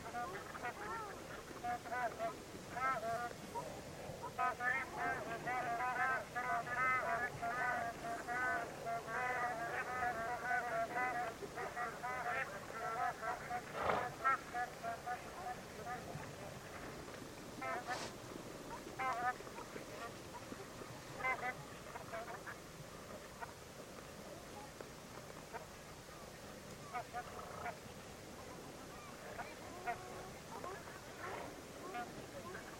Oie à tête barrée - Mes zoazos
oie-a-tete-barree.mp3